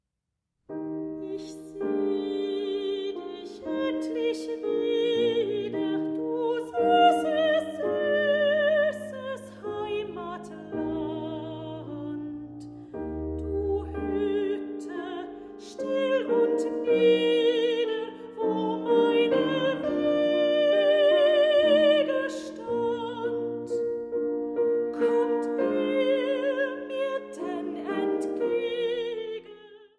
Sopran
Klavier